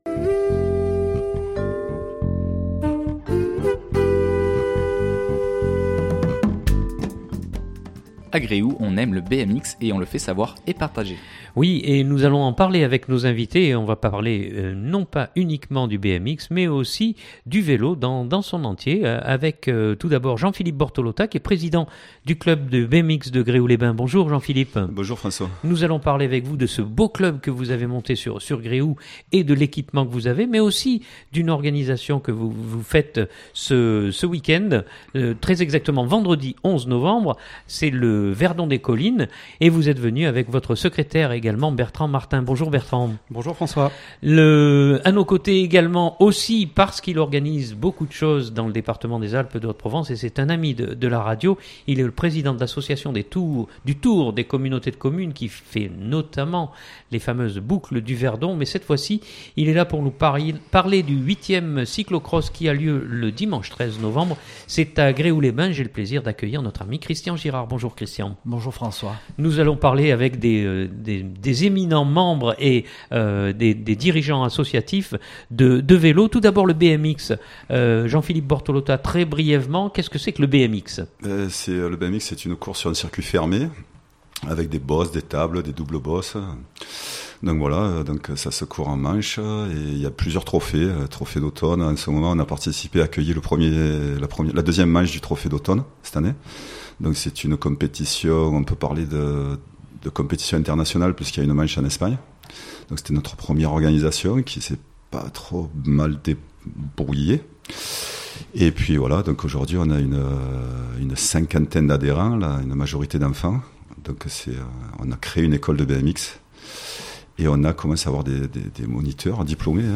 Le vélo en fête à Gréoux ce week-end ! A l'occasion de deux épreuves sportives, nous recevons trois adeptes du vélo.